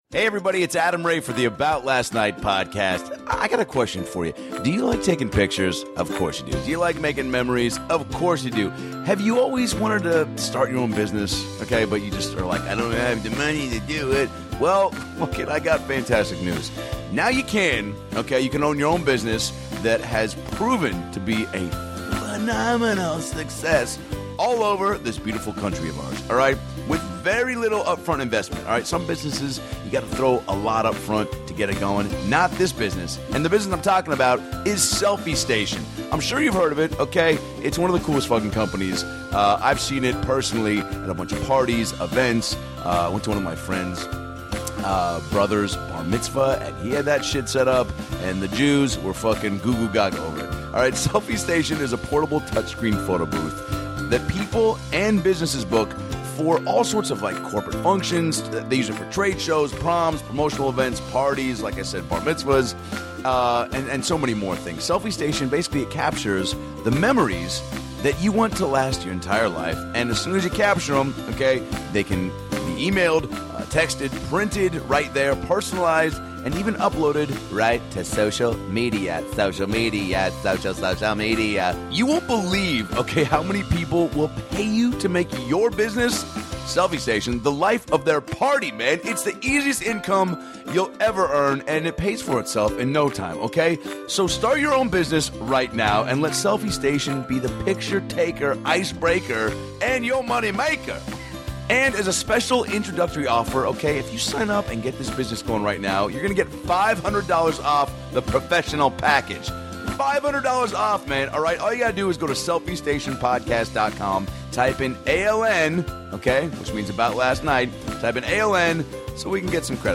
Blake Anderson stops by to talk WORKAHOLICS season 6, filming the movie DOPE, some crazy Hawaii stories with Adam Ray, and his love for Home Improvement. Non stop laughs in this one.